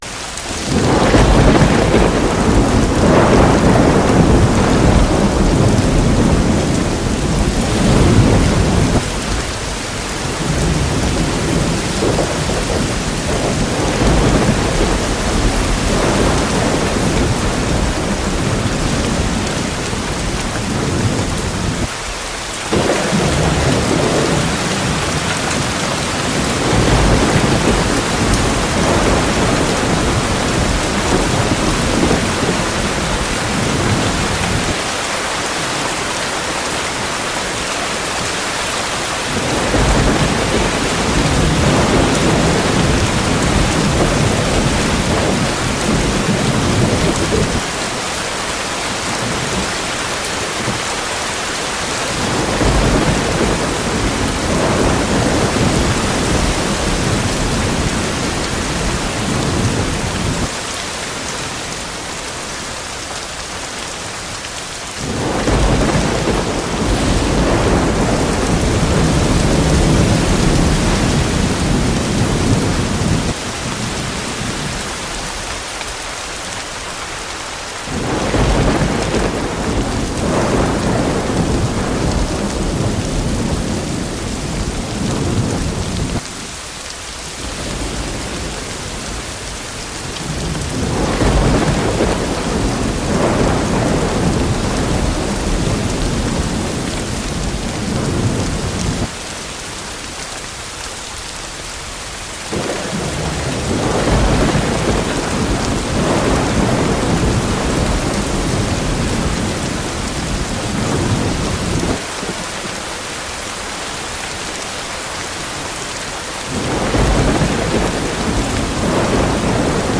Relaxing Sounds Podcasts
1 Waterfall - 10 hours for Sleep, Meditation, & Relaxation 10:00:00